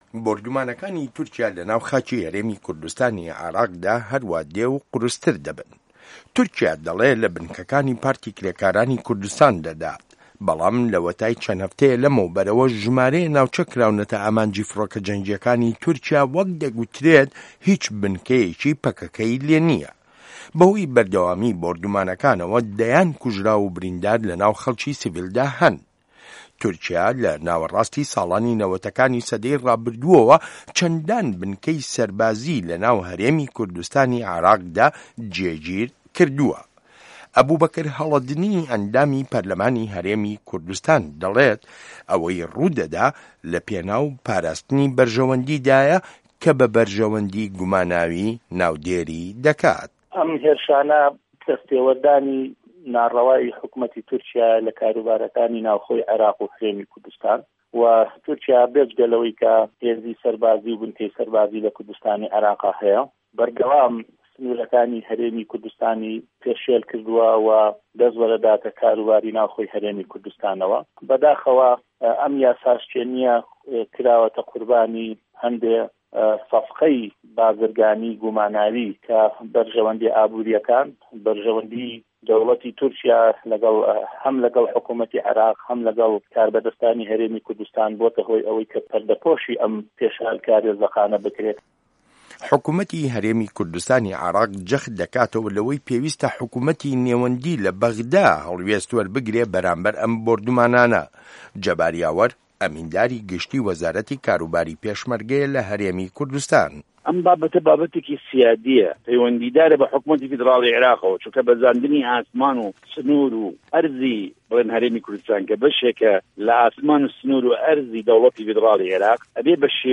ڕاپۆرتی بێ دەنگی هەردوو حکومەتی عێراق و هەرێم لە بەرامبەر بۆردومانەکانی تورکیا